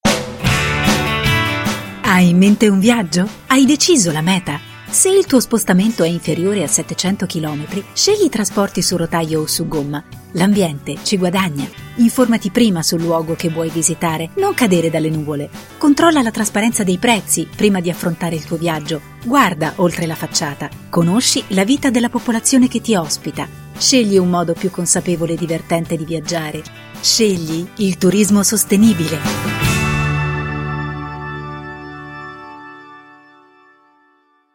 Giornalista speaker- Voice talent
Sprechprobe: Industrie (Muttersprache):